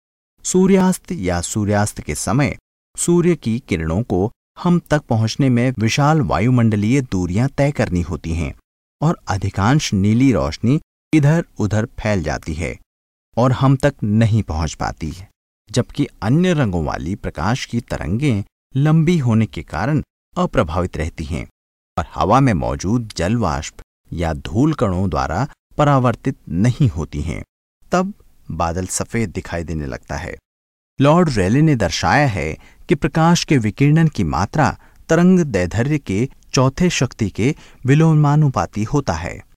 Voice Overs
HI SS EL 01 eLearning/Training Male Hindi